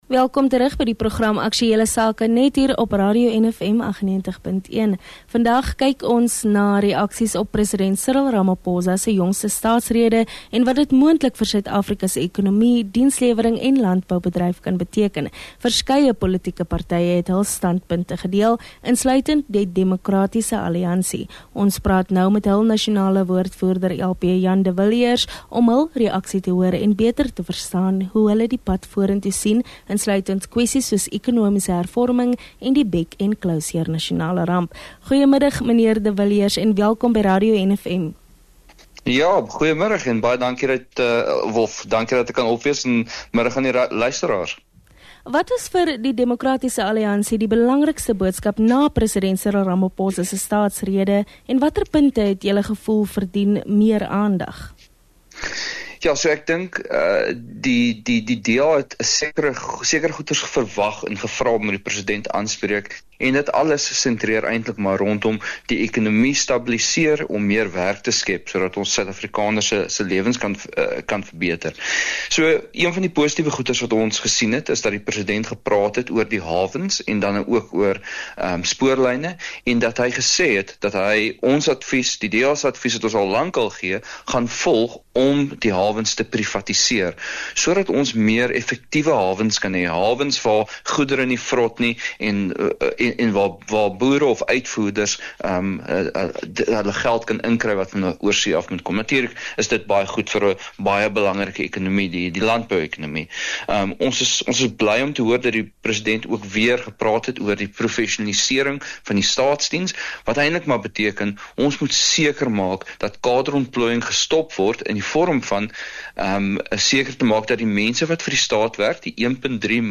From economic growth and job creation to energy security, governance, and accountability, we explore where the DA believes the government is falling short, and what alternatives they propose. This conversation offers valuable insight into the political landscape shaping South Africa’s future.